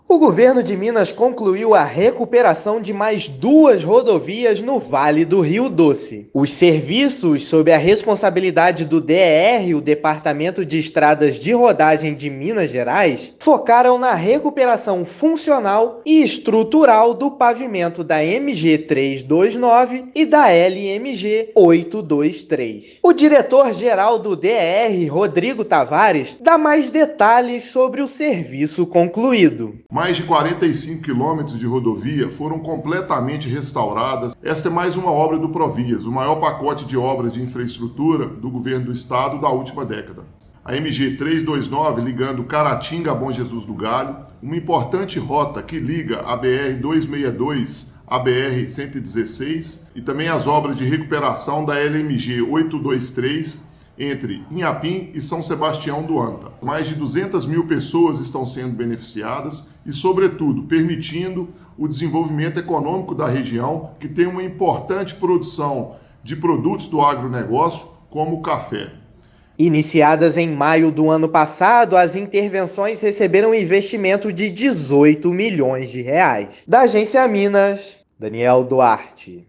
[RÁDIO] Governo de Minas conclui mais duas obras em rodovias no Vale do Rio Doce
São 45 quilômetros de pistas recuperadas e sinalizadas por meio do Provias, que irão beneficiar 200 mil mineiros. Ouça a matéria de rádio: